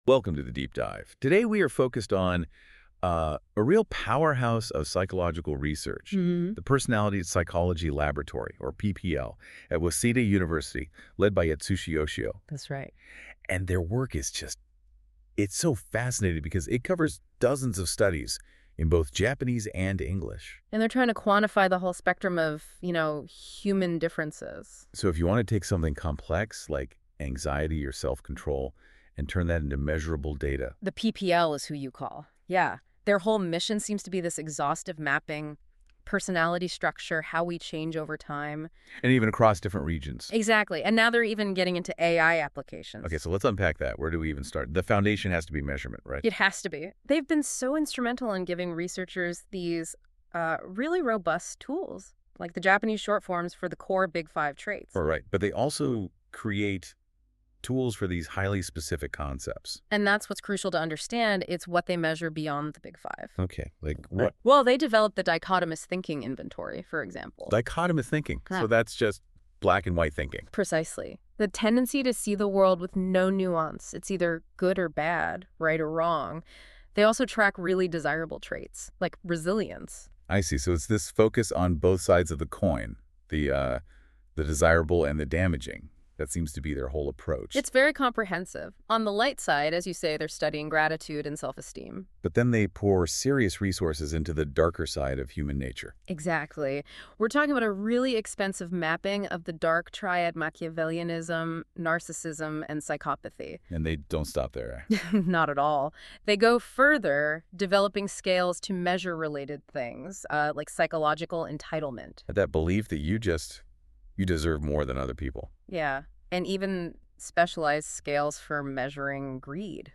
AI-generated audio commentary about research interest in this lab